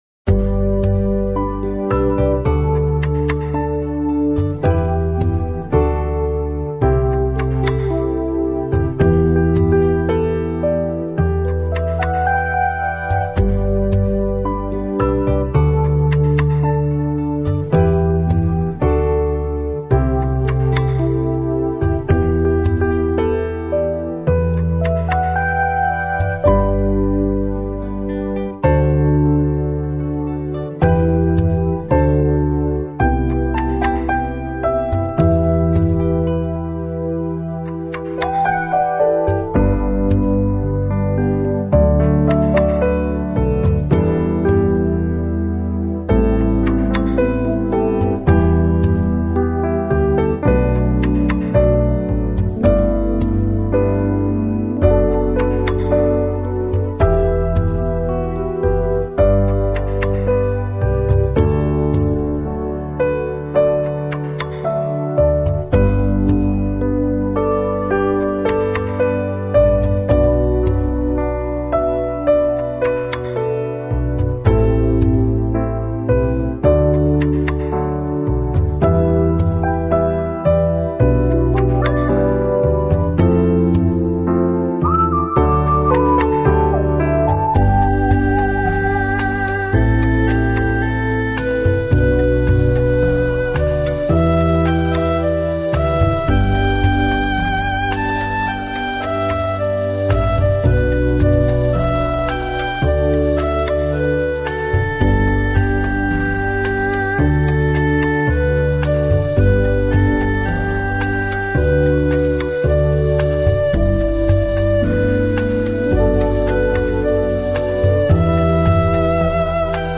版本：佛教轻音乐．全套四张
中國樂器的風韻在禮讚之中找到它的新衣
听过以后特别能让内心平静,有种说不出的宁静、安详!!!